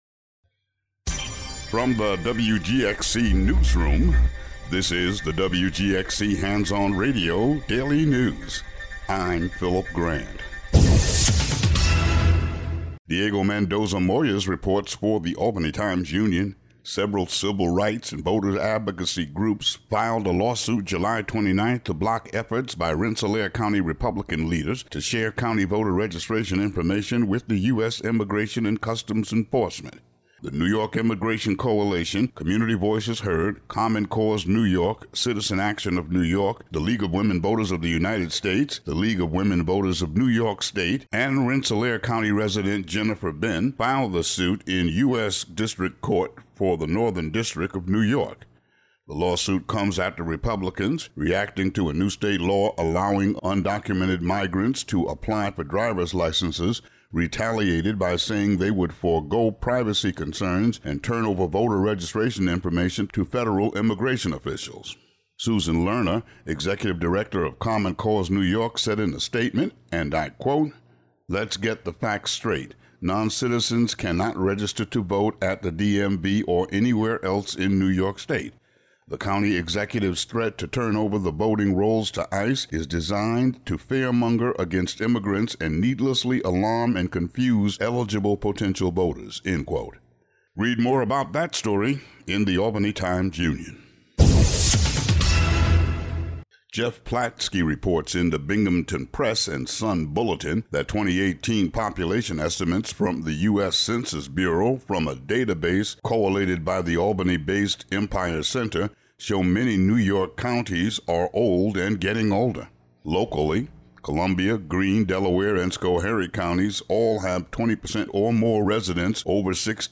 Uncategorized Local headlines and weather